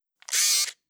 Plasma Cutter Rotation Sound.wav